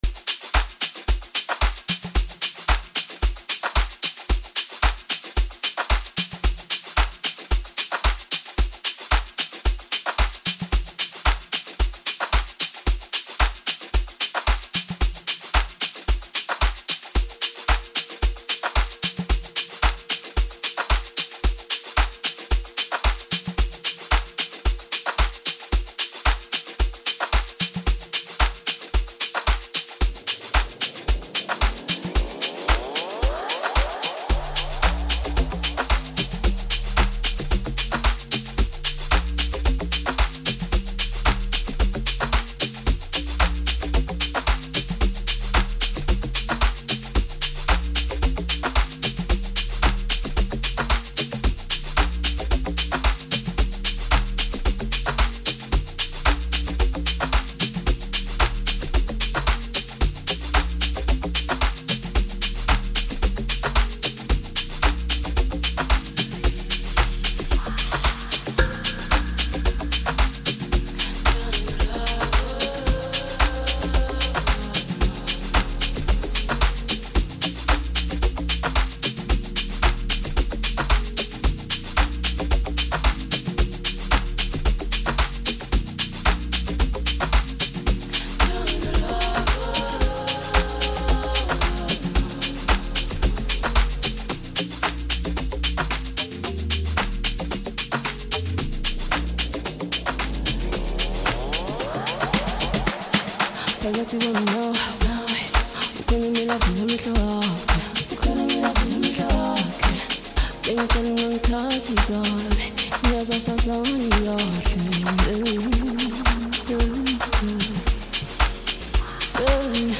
Amapiano, DJ Mix, Hip Hop